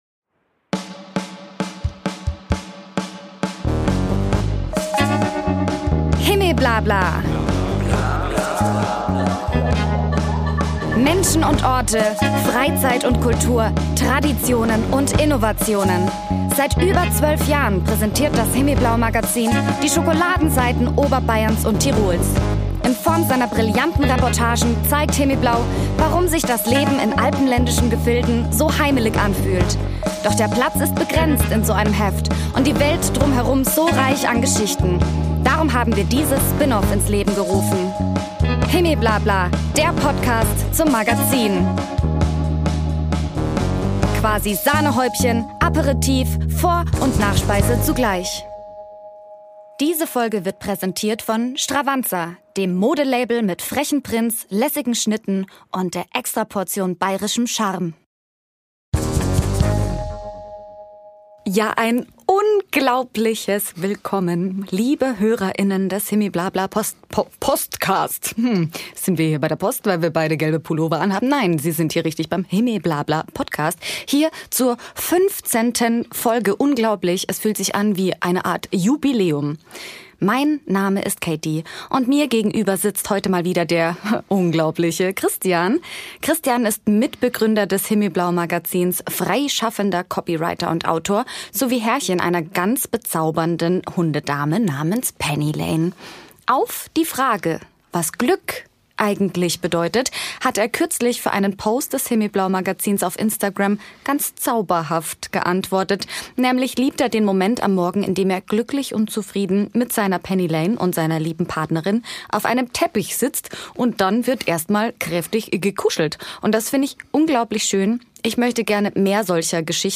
Folge 15 – zwei hibbelige Hosts treffen auf eine Ikone der Hörbuchkunst – da sperren nicht nur Fourth-Wing-Fans die Lauscherchen auf.